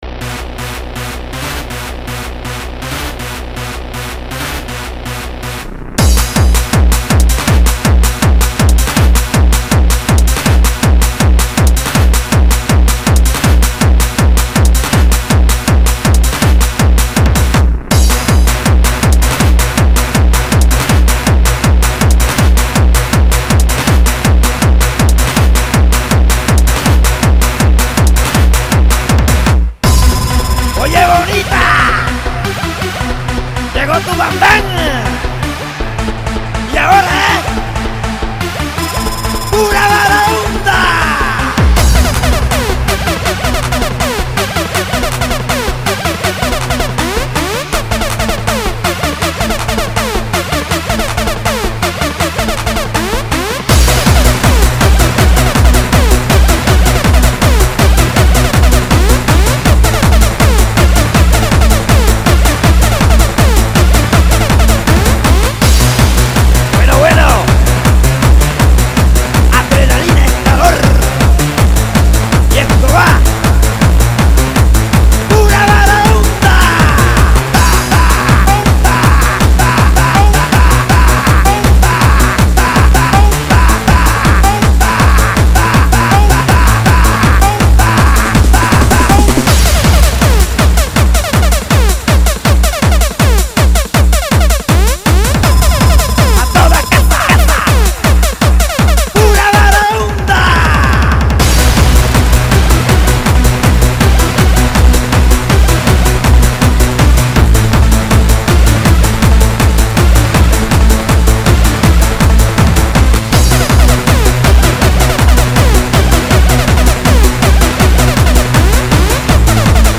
BPM40-161
Italian Dance Act
had a Spanish speaker within their songs